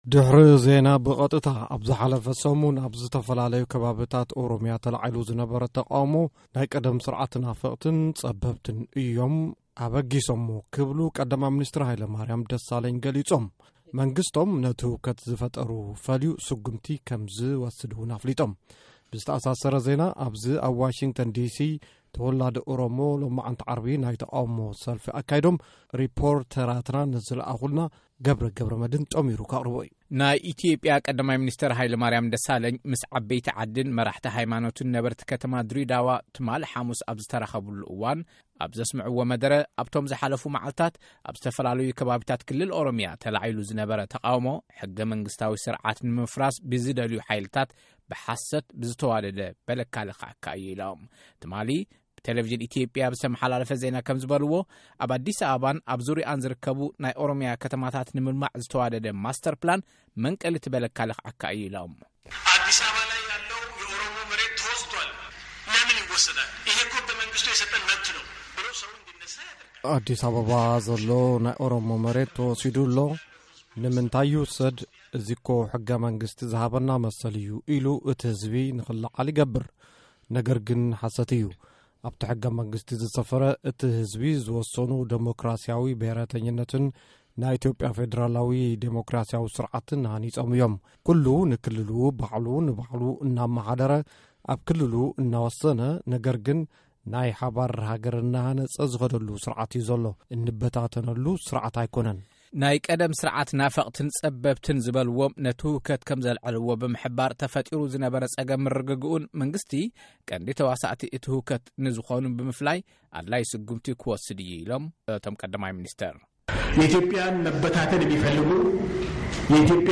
ኣብ ዝሓለፈ ሰሙን ኣብ ዝተፈኣላለዩ ከባቢታት ክልል ኦሮሚያ ተላዒሉ ዝነበረ ተቓውሞ ናይ ቀደም ስርዓት ናፈቕትን ፀበብትን እዮም ኣበገስቱ ክብሉ ቀዳማይ ምንስቴር ሃይለማሪያም ደሳለኝ ኸሲሶም።መንግስቶም ነቲ ህውከት ዝፈጠሩ ፈልዩ ስጉምቲ ከምዝወስድ እውን ገሊፆም። ምስ ዓብየቲ ዓዲን መራሕቲ ሃይማኖትይን ነበርቲ ከተማ ድሪዳዋ ትማሊ ሓሙስ ኣብዝተራኸብሉ እዋን ኣብ ሰስምዕዎ መደረ ኣብቶም ዝሓለፉ ማዕልታት ኣብ ዝተፈላለዩ ከባቢታት ክልል ኦሮሚያ ተላዒሉ ዝነበረ ተቓውሞ...